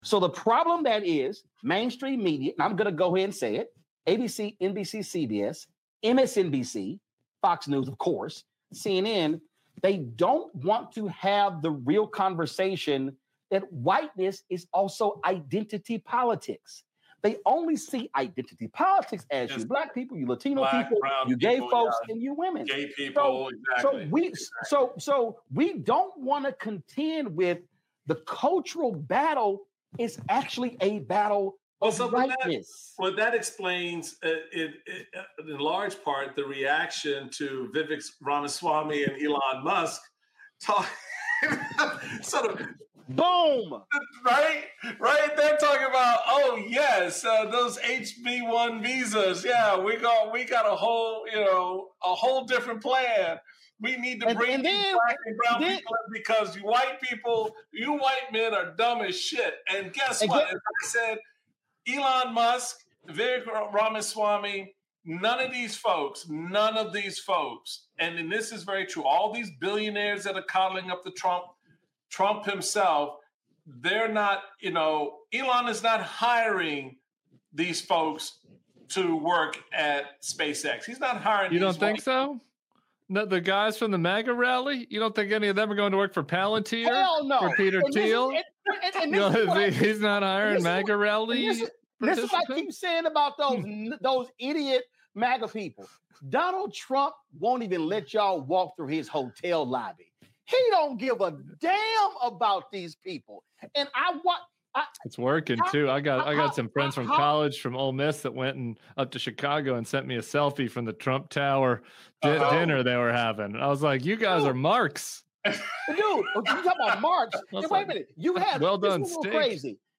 Michael Steele speaks with Tim Miller and Roland Martin about identity politics, how it informed the 2024 election and how to engage in identity politics "the right way."